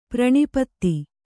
♪ praṇipatti